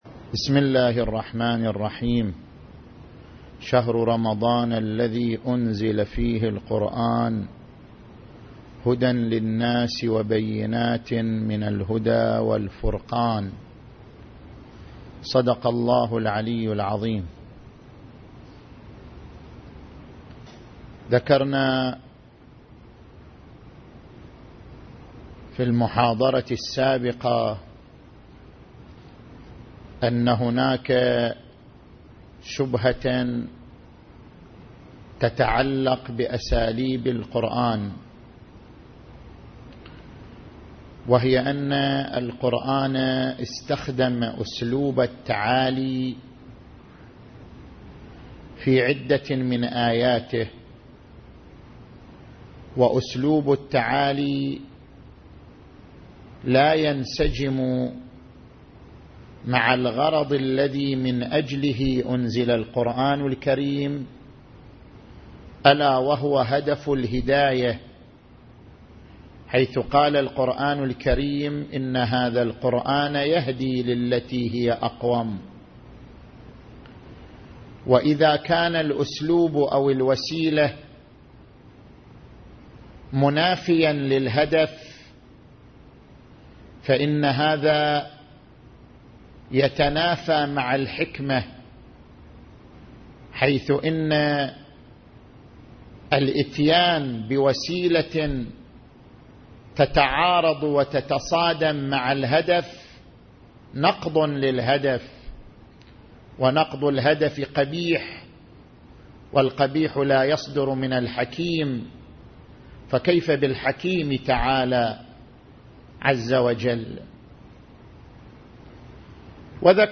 رمضان المبارك 1433 هـ